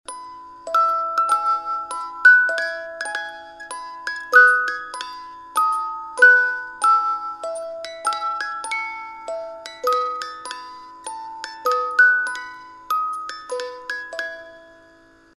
Рождественская мелодия в старинной шкатулке